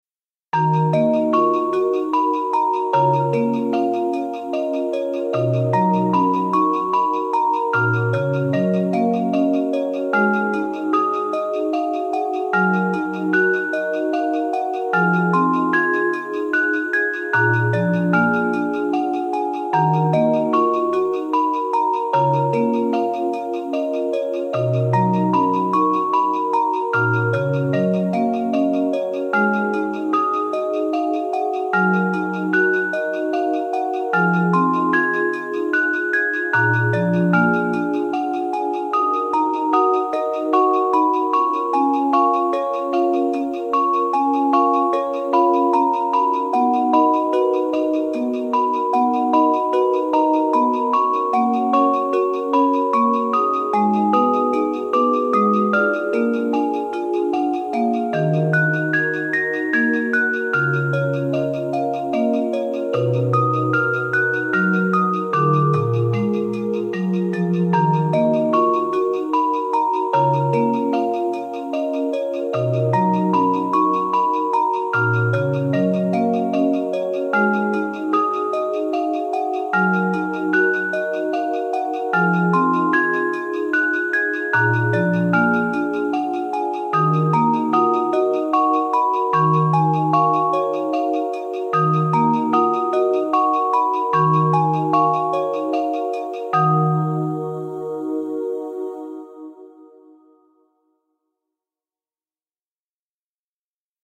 2018.11 | 悲しい | ニューエイジ | 1分44秒/1.59 MB